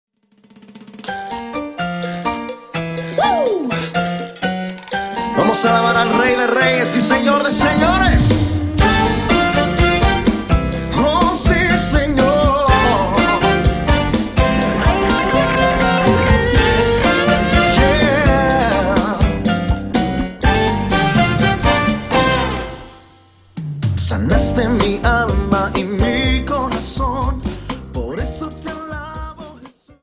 Dance: Cha Cha 31 Song